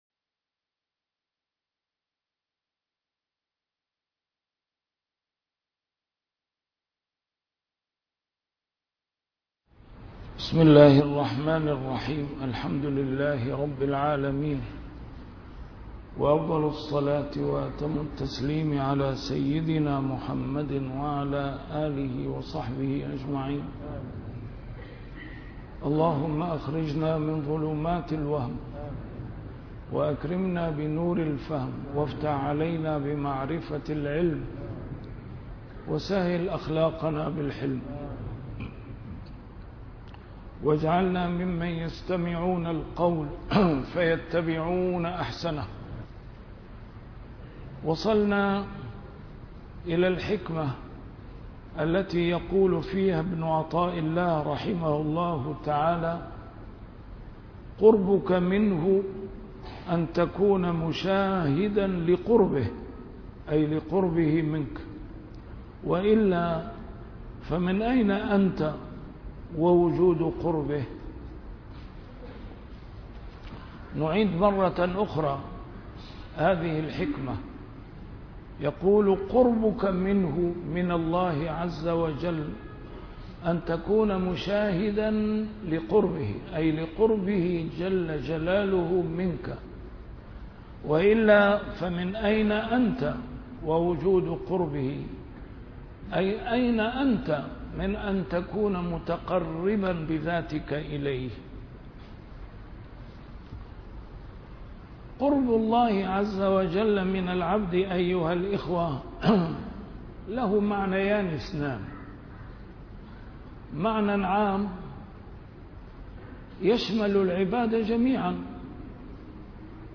الدرس رقم 235 شرح الحكمة رقم 213 (تتمة)